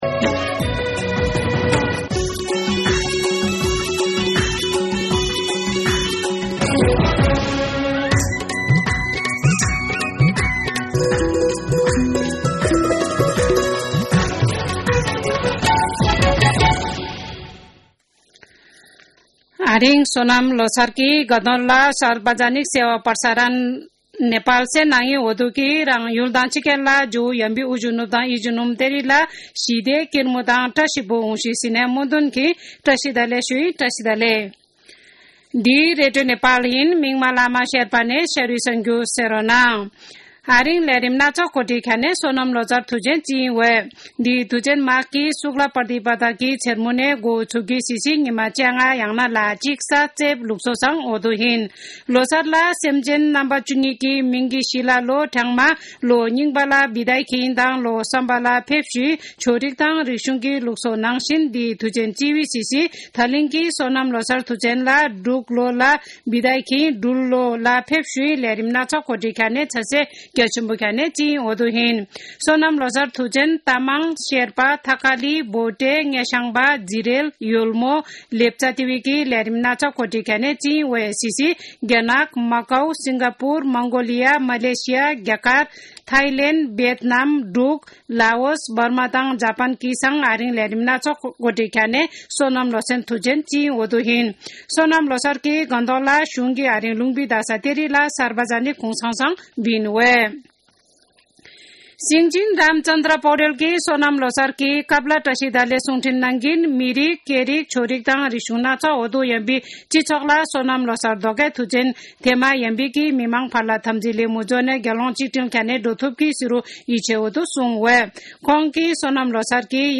शेर्पा भाषाको समाचार : १८ माघ , २०८१
Sherpa-News-10-17.mp3